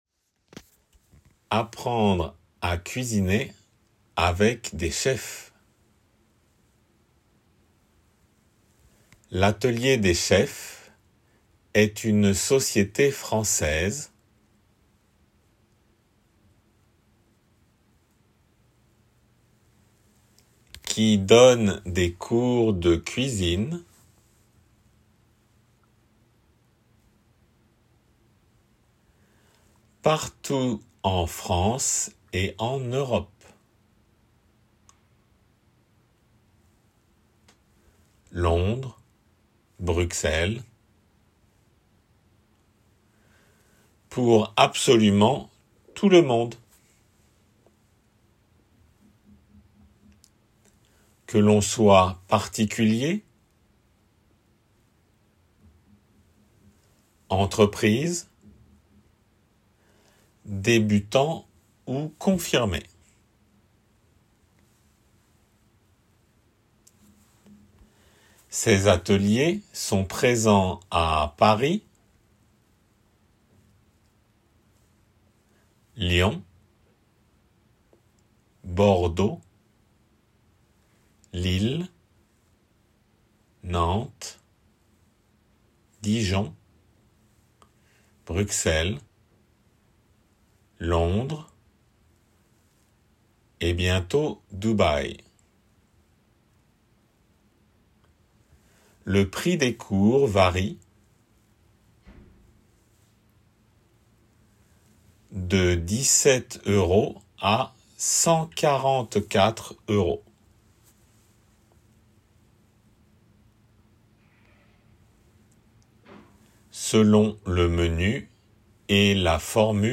音声に雑音―最後の部分に。